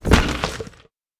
Foley Sports / Basketball / In The Net A.wav